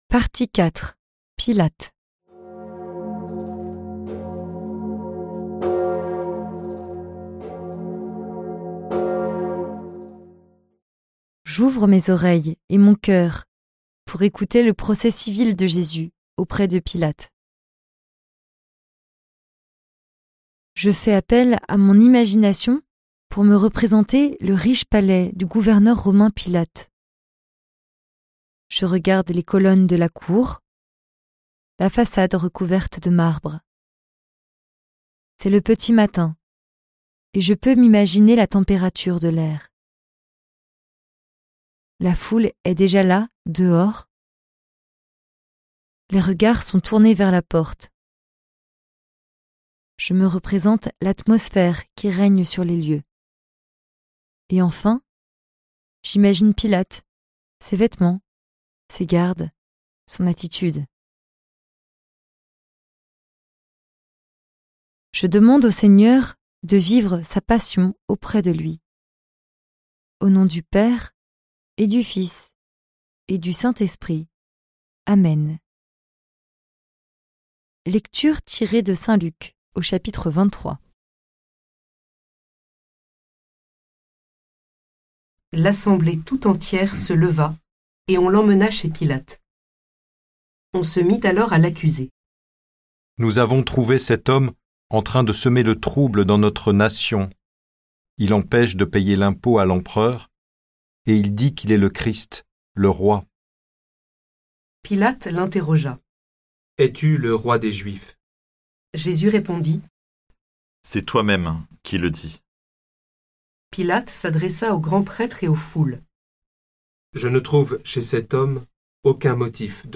Méditation guidée suivant un livre biblique, une encyclique, des psaumes, ou un thème de la vie chrétienne.